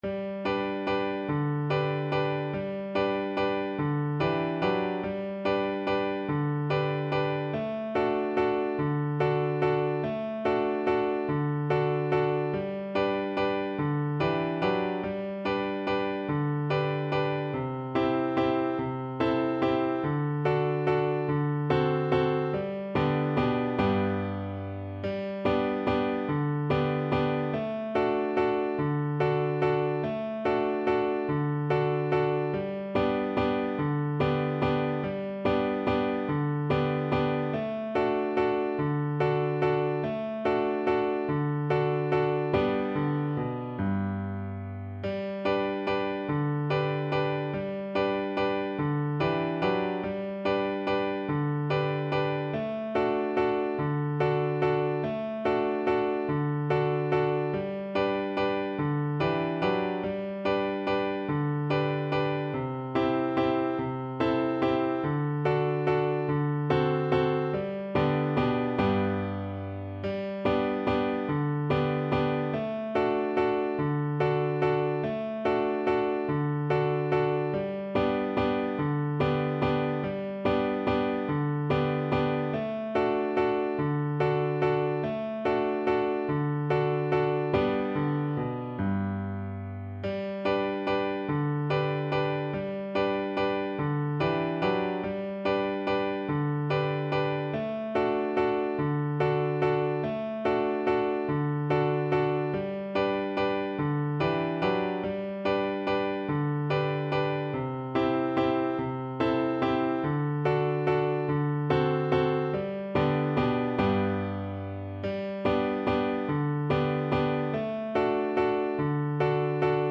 3/8 (View more 3/8 Music)
Steady one in a bar .=c.48
Traditional (View more Traditional Violin Music)